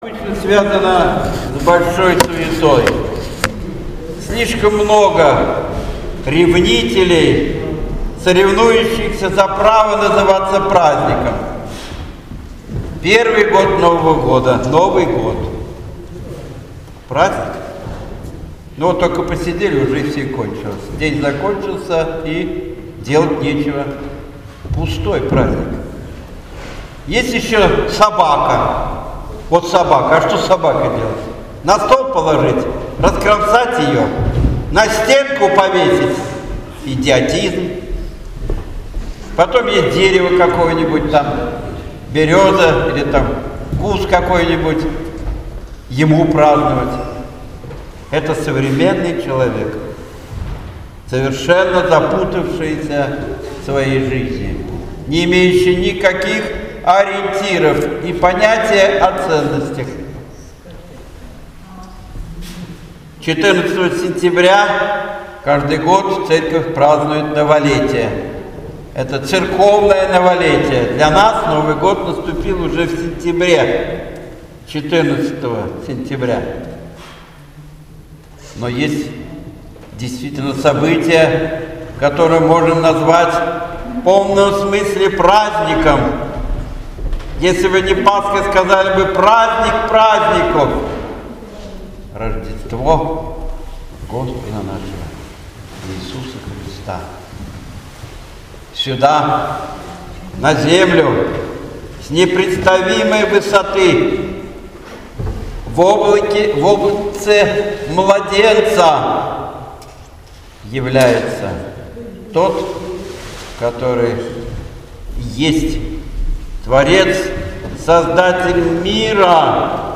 7 января 2018 г. Праздничная Литургия в церкви во имя Покрова Божией Матери